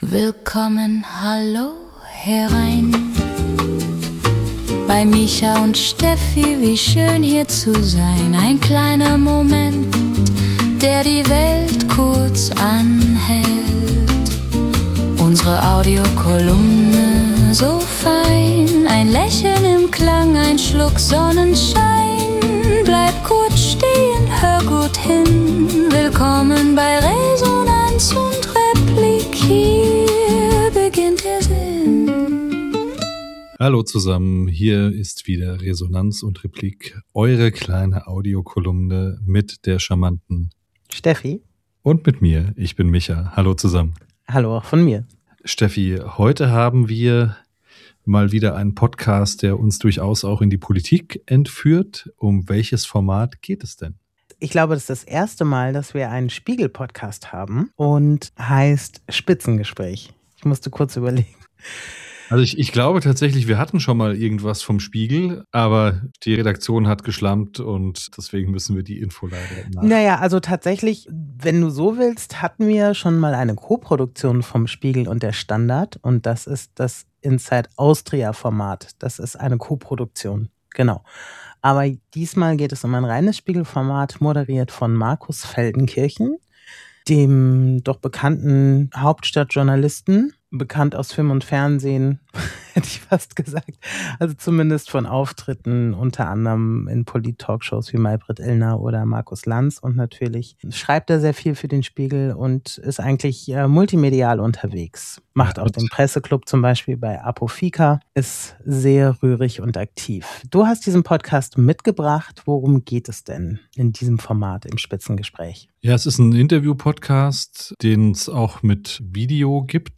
Die beiden Hosts diskutieren die Besonderheiten des Podcasts, der sich durch tiefgehende Interviews mit interessanten Gästen auszeichnet, eben auch der Historiker Christopher Clark.